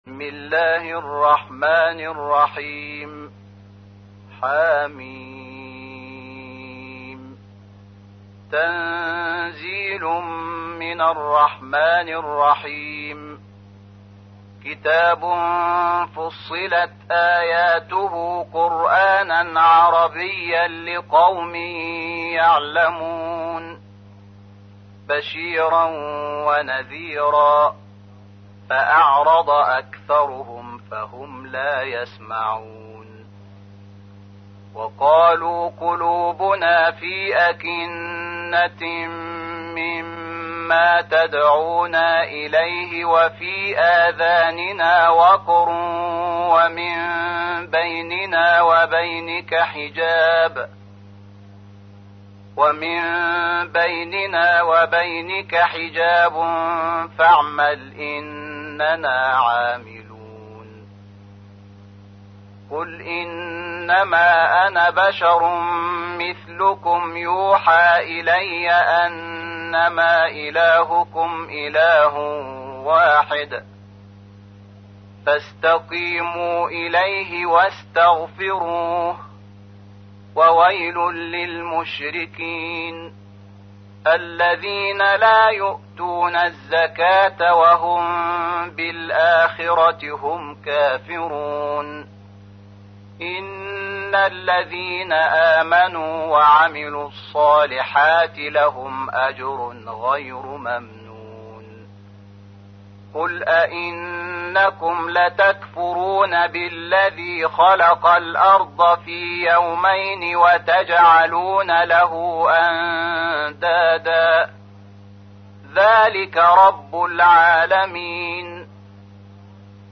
تحميل : 41. سورة فصلت / القارئ شحات محمد انور / القرآن الكريم / موقع يا حسين